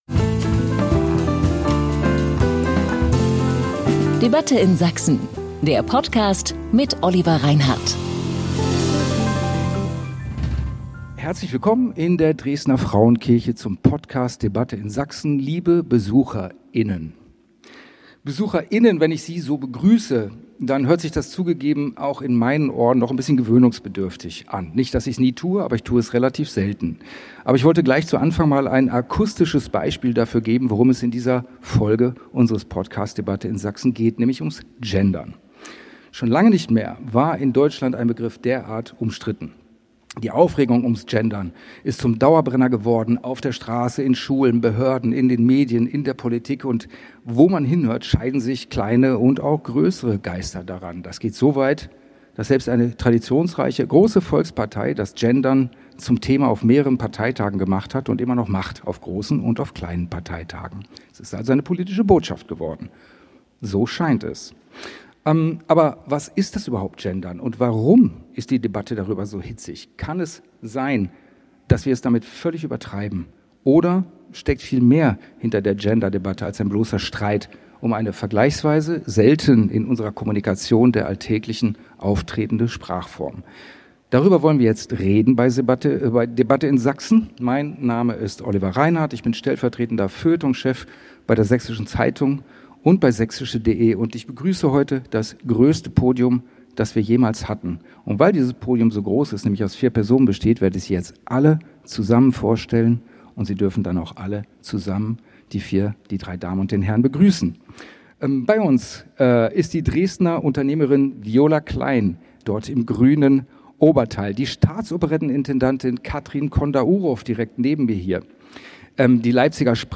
Die neue Folge im Podcast "Debatte in Sachsen" widmet sich dem Trubel ums Gendern auf einer Live-Diskussion in der Frauenkirche.